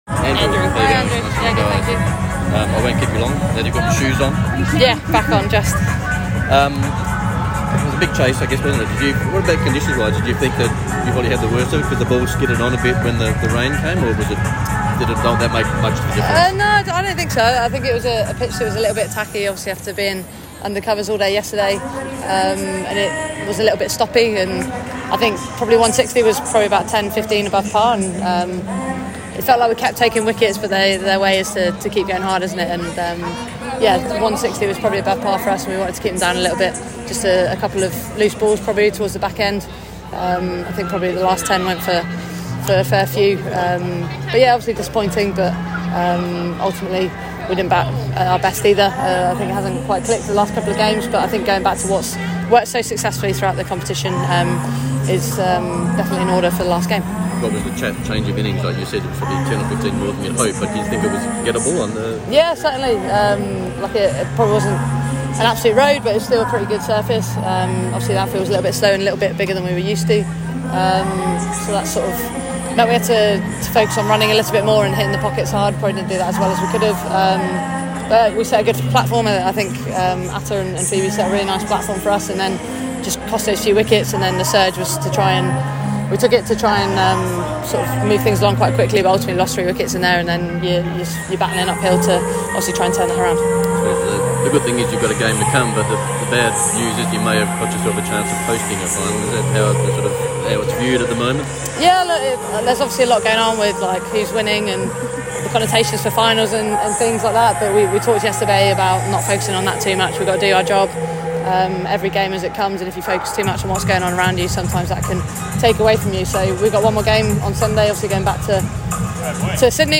Sydney Thunder captain Heather Knight after Thunder’s 22 run loss to Brisbane Heat.